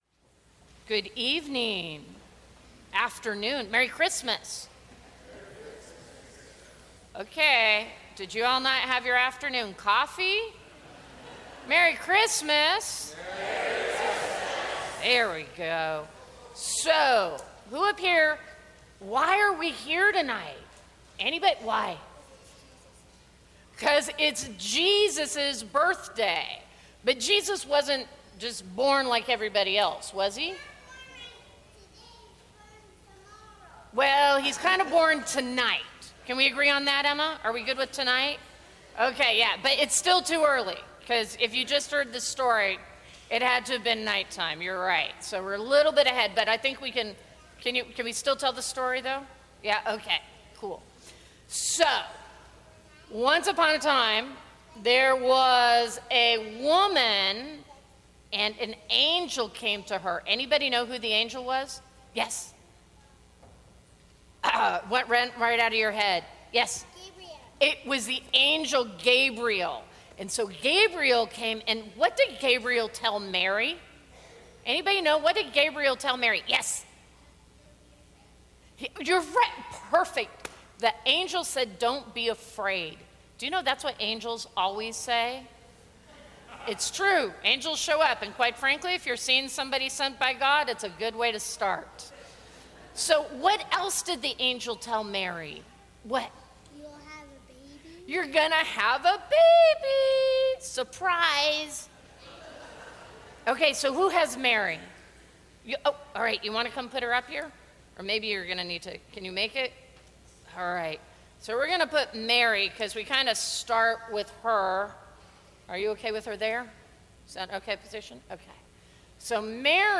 Christmas Eve Parish Family Worship